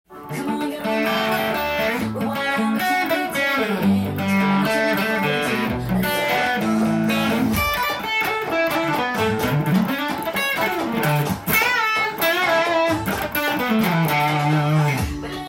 音源に合わせて譜面通り弾いてみました
最初の４小節は、少し複雑なリズムでアフリカンな雰囲気のする
アルペジオのギターパートになっています。
３段目からは、打楽器とのギターデュオのような構成になり
Bマイナーペンタトニックスケールから始まり
Bｍ７（９）のスィープピッキングが入ったフレーズになっています。